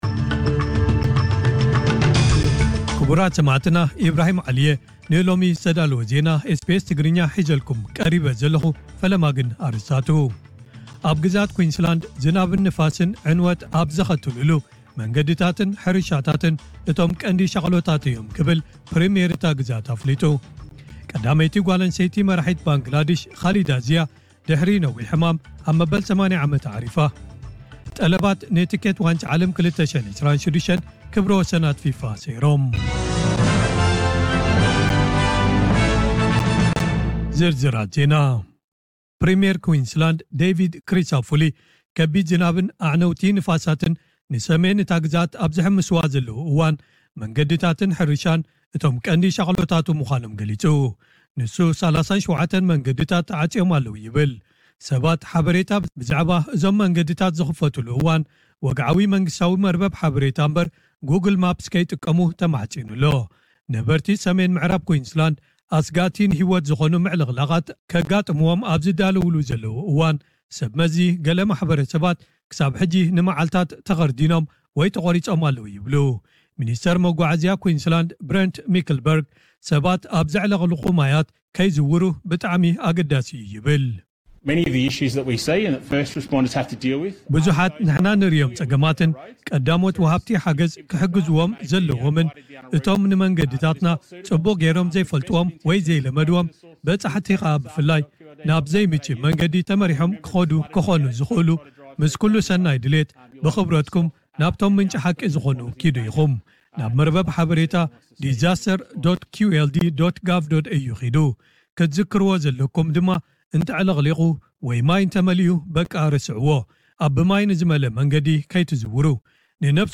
ዕለታዊ ዜና ኤስቢኤስ ትግርኛ (01 ጥሪ 2025)